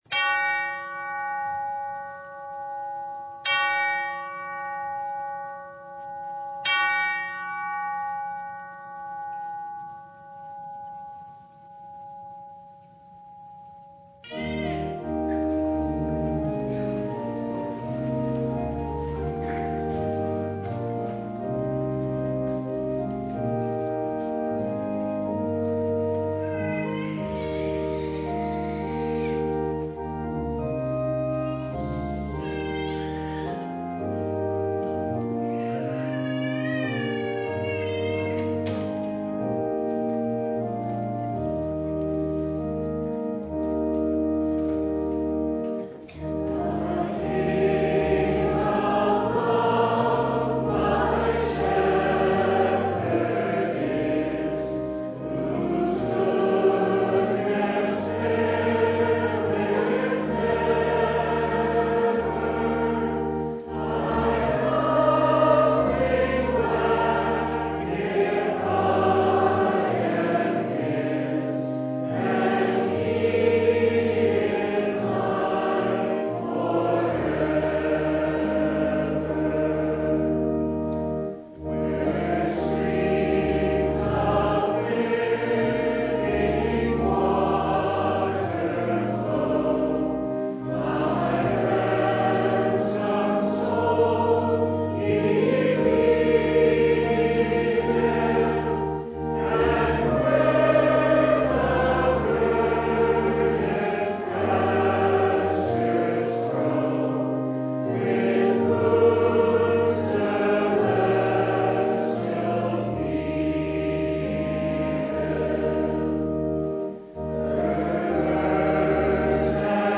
Passage: Mark 6:30-44 Service Type: Sunday